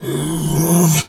bear_pain_hurt_groan_01.wav